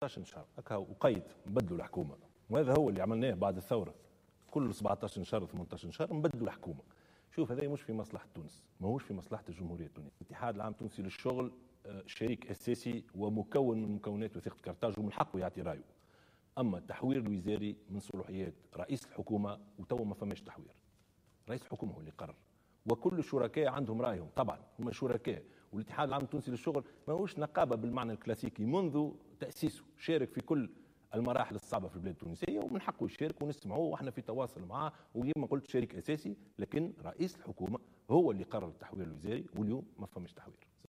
أكد رئيس الحكومة يوسف الشاهد في حوار على القناة الوطنية الأولى مساء اليوم الأحد أنه لا وجود لتحوير وزاري منتظر في حكومته تعليق له على دعوة أمين عام المنظمة الشغلية نور الدين الطبوبي في تجمع للمنظمة النقابية في مدينة سيدي بوزيد ، إلى إجراء تعديل وزاري لإضفاء فعالية أكبر على عمل الحكومة.